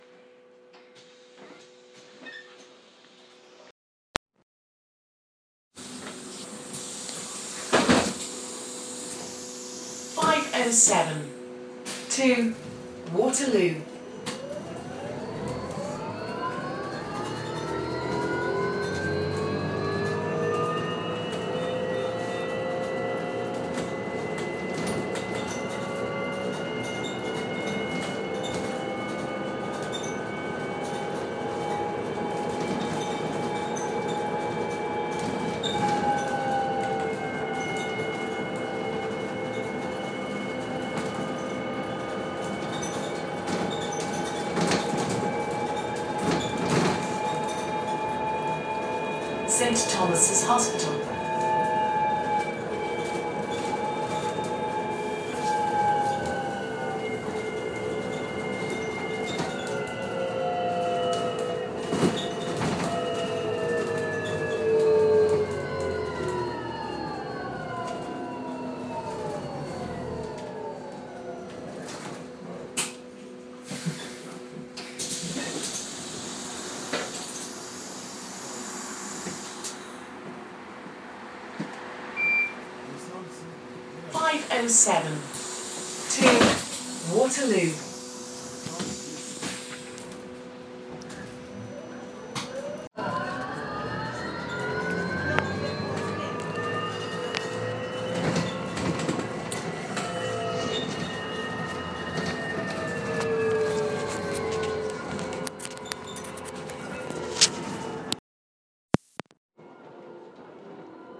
A short trip on possibly the noisiest electric bus around
But here it is in all its glory, travelling one stop between Lambeth Palace and St Thomas Hospital. I always thought that electric vehicles were meant to be quiet, this one certainly isn't though!